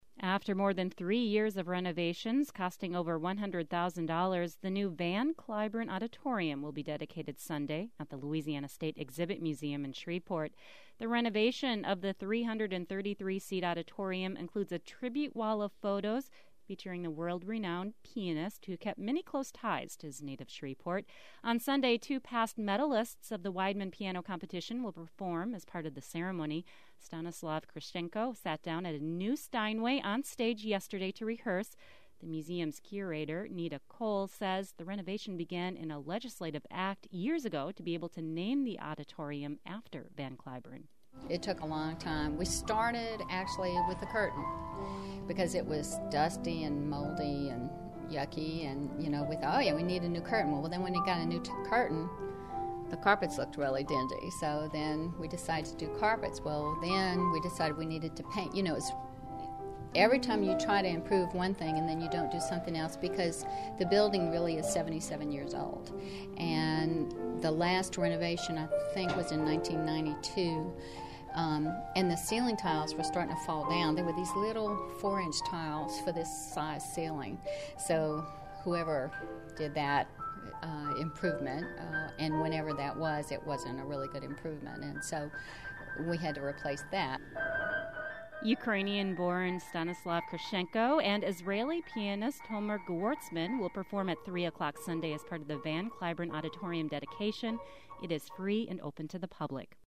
Louisiana News